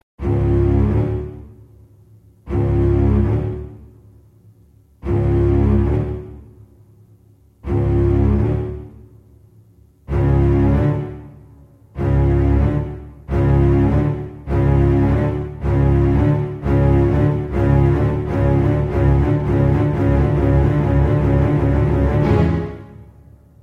Категория: Музыка из фильмов ужасов